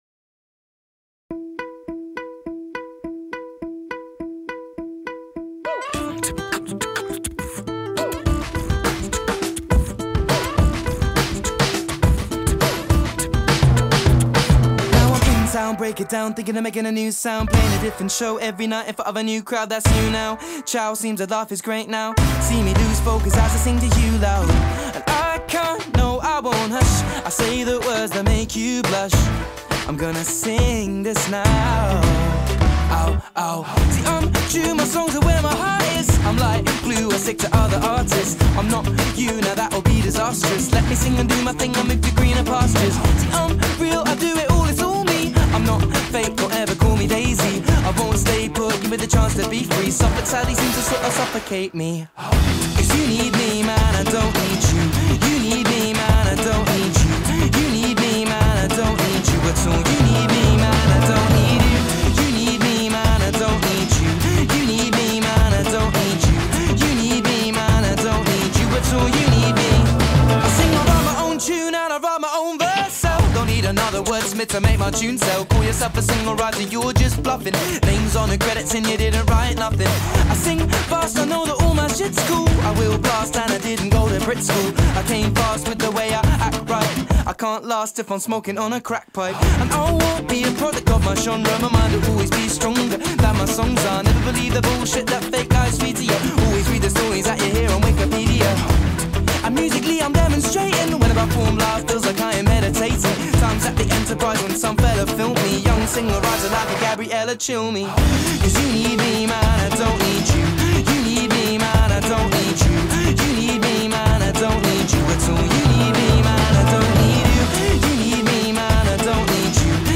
Pop, Folk Pop